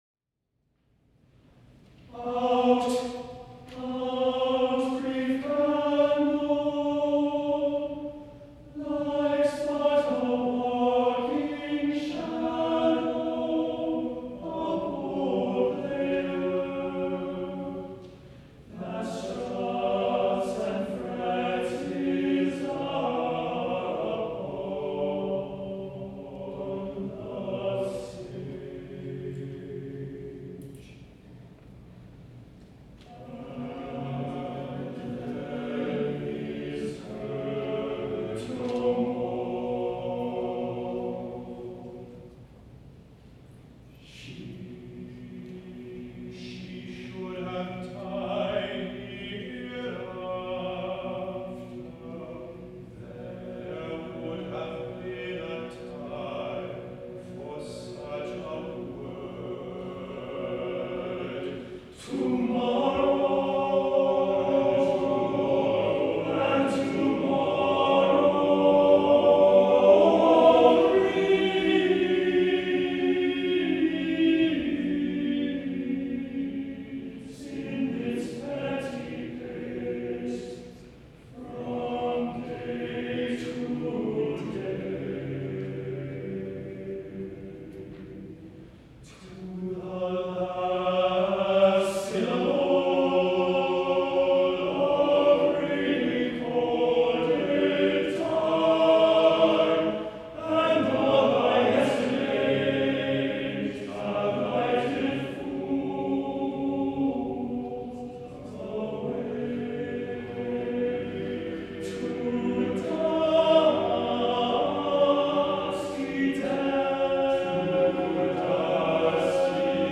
for TTBB chorus a cappella